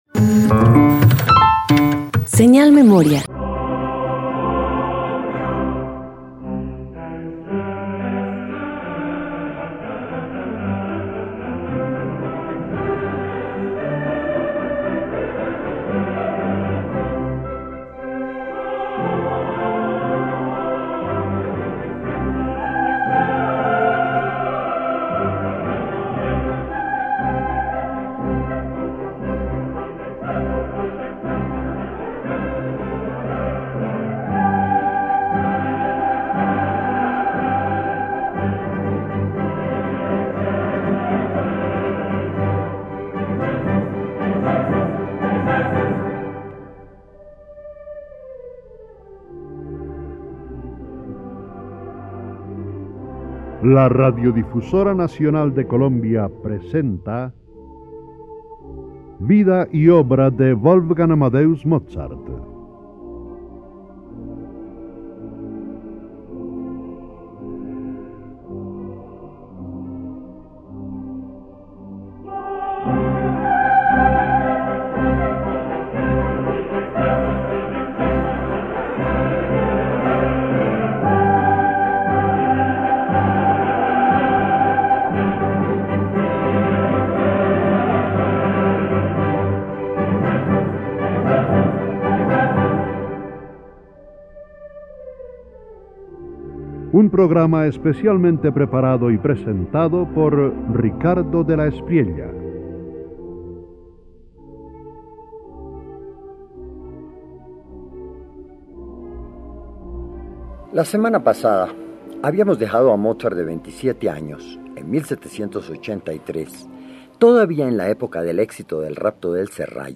Radio colombiana
Cuarteto de cuerdas en Re menor